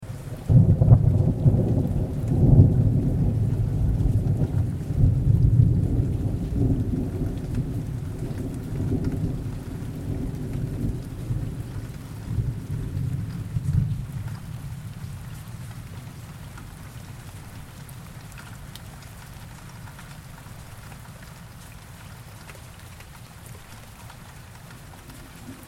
دانلود آهنگ طوفان 22 از افکت صوتی طبیعت و محیط
جلوه های صوتی
دانلود صدای طوفان 22 از ساعد نیوز با لینک مستقیم و کیفیت بالا